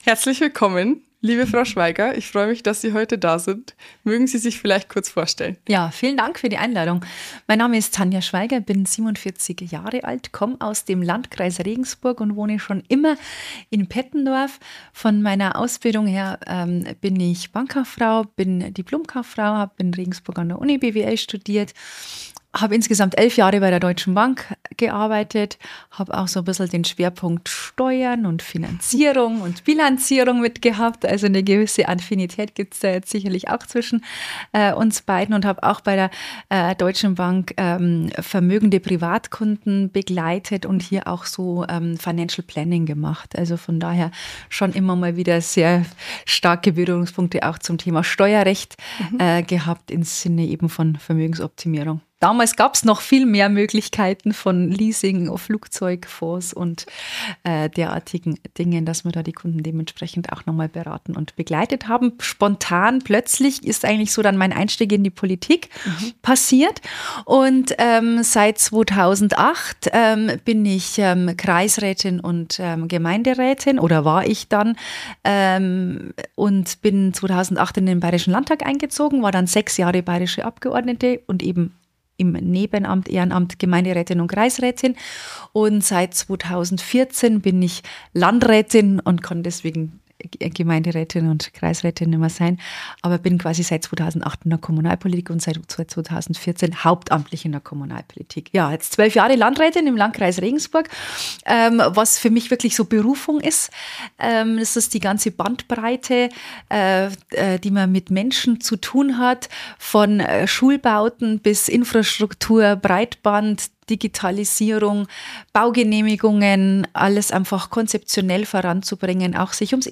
Folge 19 – Im Gespräch mit der Landrätin ~ wiba.talks Podcast